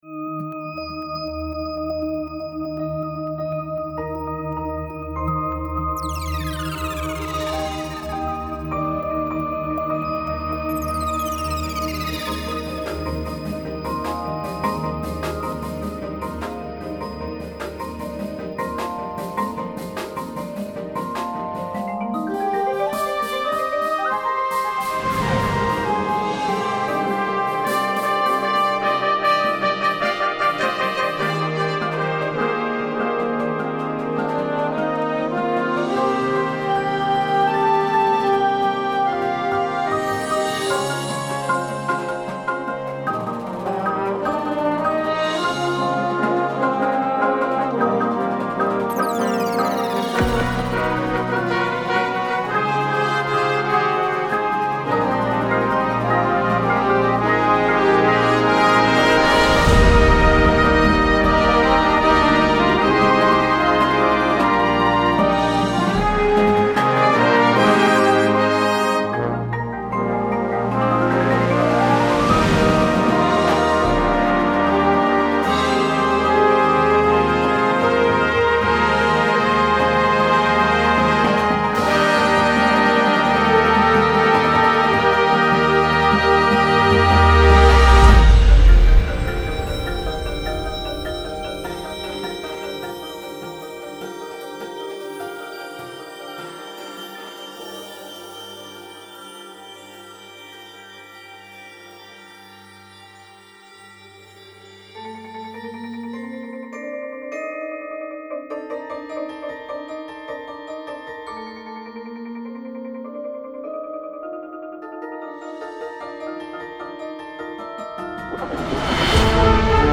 a marching band show
Complete show (with optional sound design)